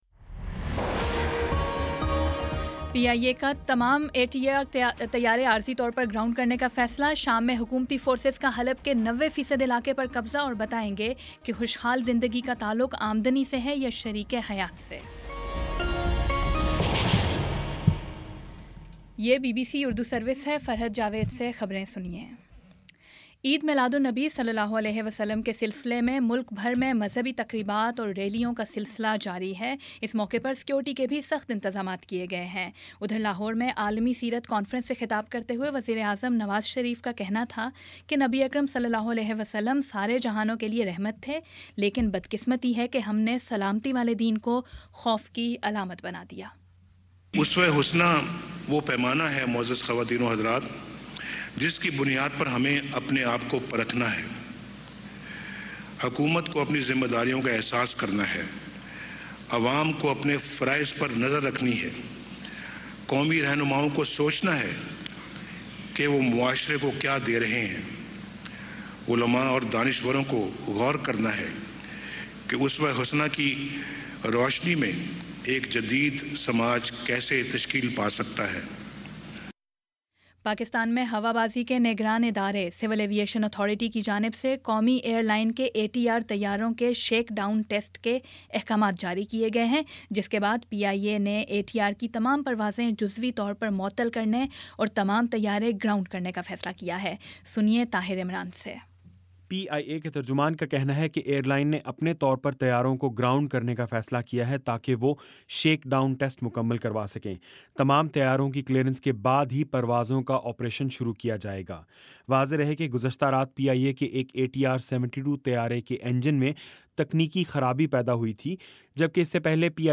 دسمبر 12 : شام پانچ بجے کا نیوز بُلیٹن